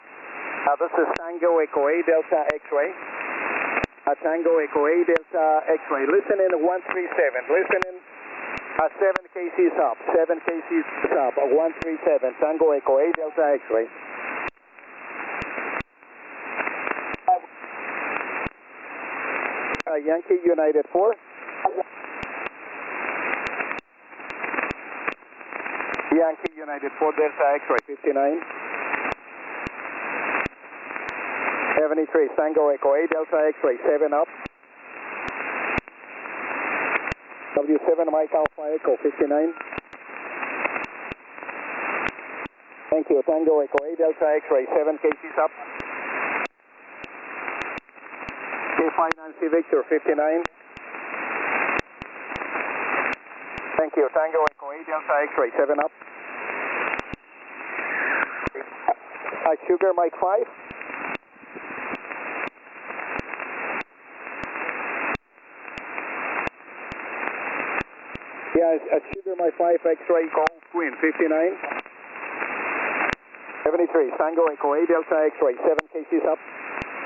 TE8DX - Chira Island (NA-116) on 18MHz SSB